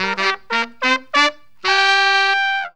HORN RIFF 11.wav